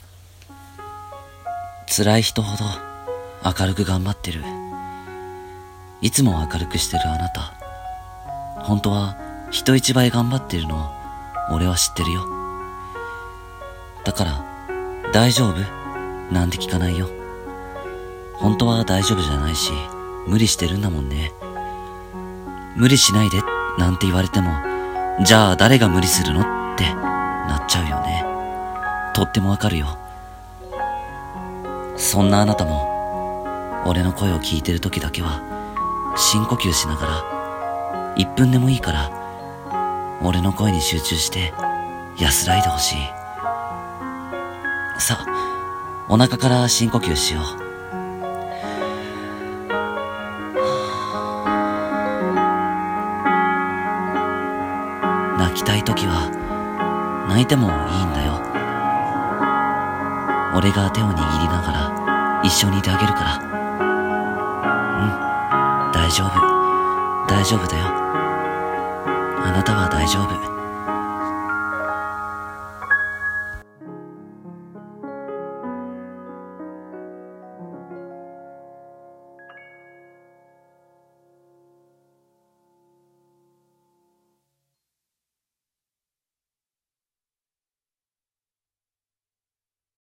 台本 癒し系「頑張ってるあなたへ」